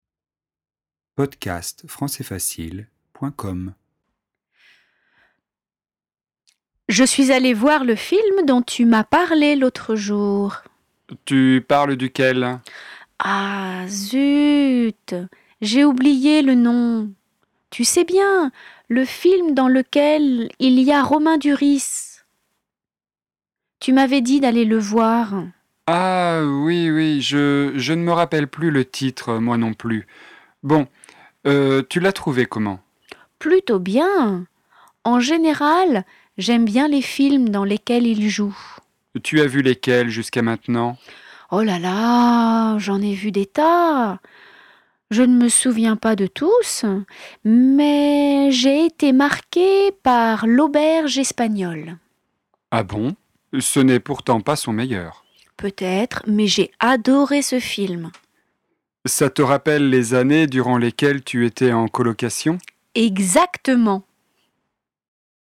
Situation: Martin et Zoé discutent des films qu'ils ont aimés.
🎧  Dialogue :